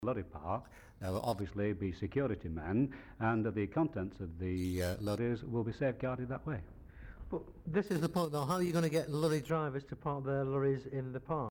The audio recording will sound more muffled and there may be an audible squeaking from the tape playing on the machine.
The above audio was digitised from a tape with sticky shed.